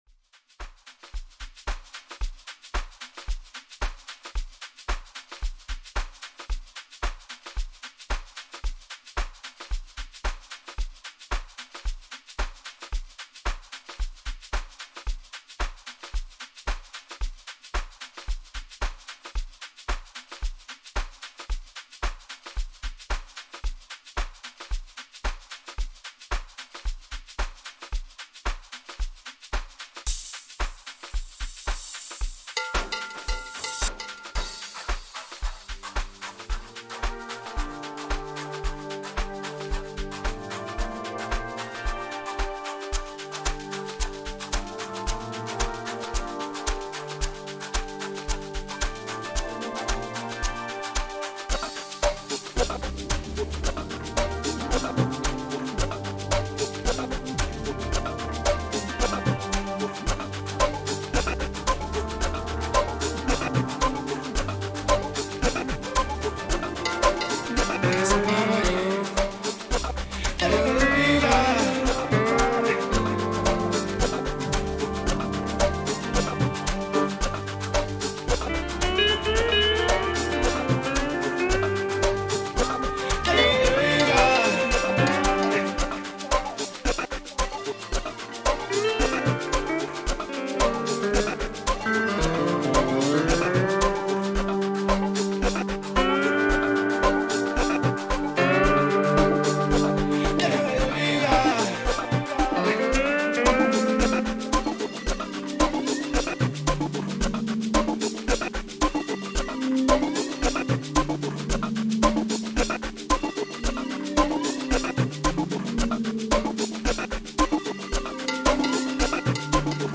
Amapiano song